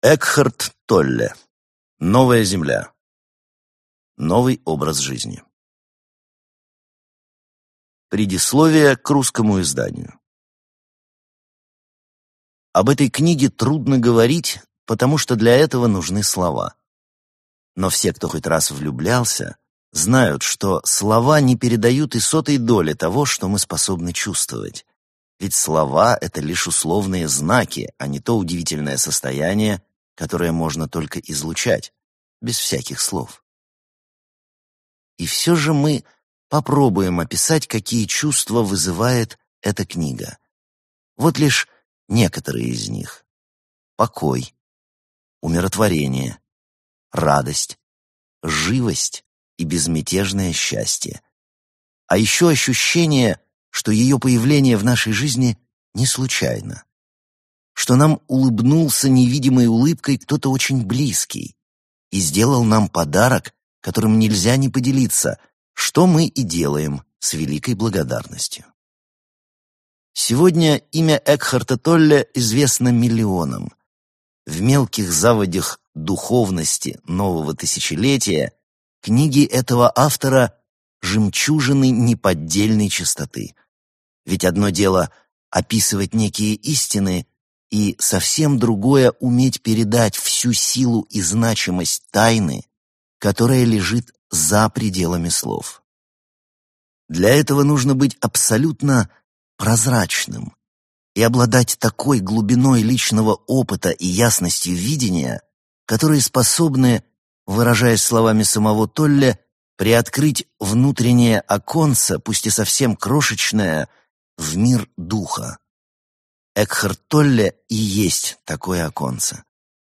Аудиокнига Новая земля. Пробуждение к своей жизненной цели | Библиотека аудиокниг